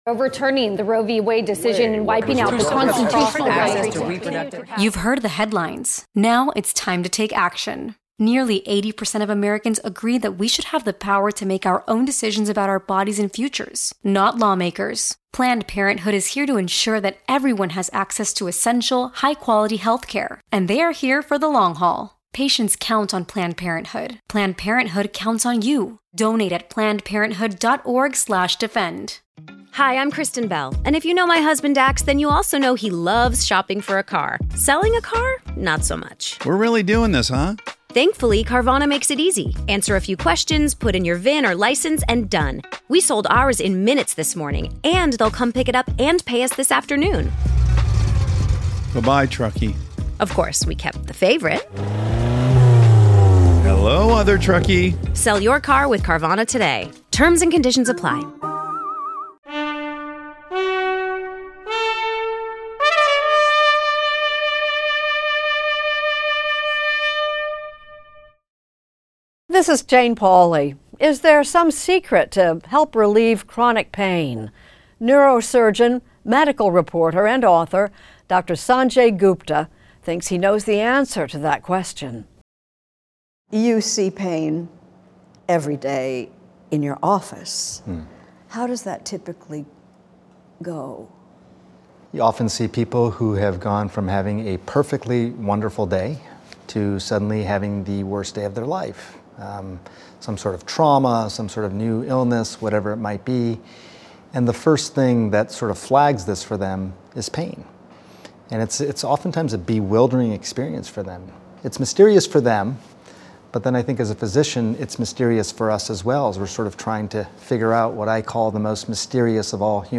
Extended Interview: Dr. Sanjay Gupta